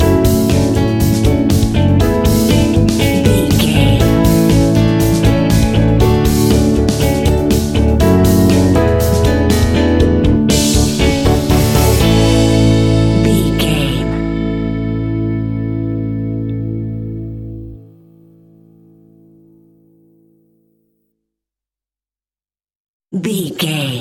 Ionian/Major
indie pop
energetic
uplifting
upbeat
groovy
guitars
bass
drums
piano
organ